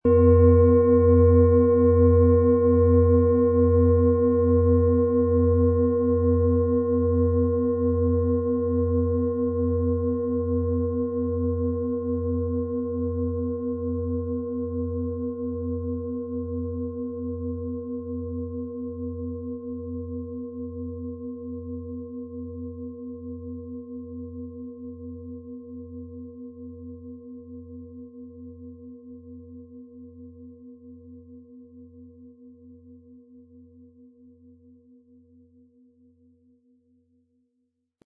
Unter dem Artikel-Bild finden Sie den Original-Klang dieser Schale im Audio-Player - Jetzt reinhören.
Aber dann würde der kraftvolle Klang und das einzigartige, bewegende Schwingen der traditionsreichen Herstellung fehlen.
PlanetentonSonne & Biorhythmus Geist (Höchster Ton)
MaterialBronze